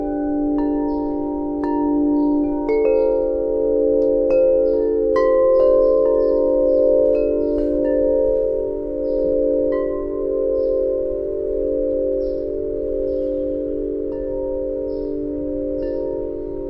铃铛
描述：风铃
标签： 风铃
声道立体声